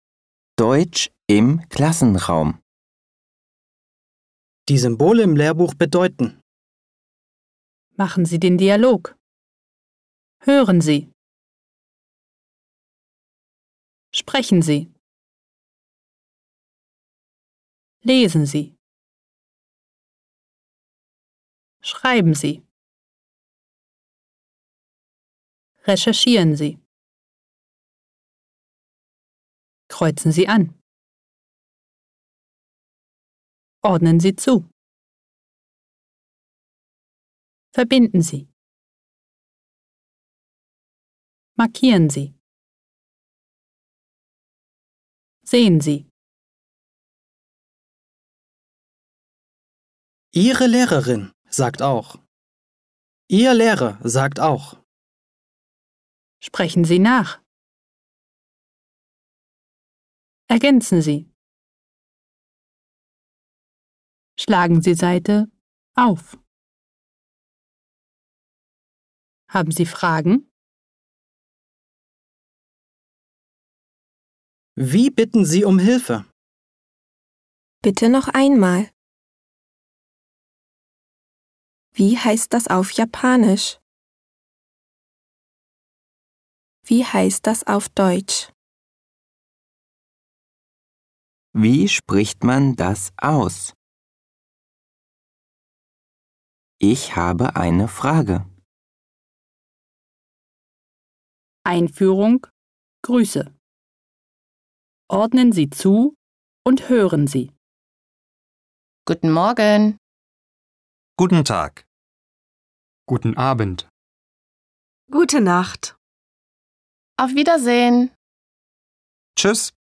聞き取り問題の音声には，背景音をいれないゆっくり発話したものも収録し，学習者の負担を減らしました（QRコード付き）。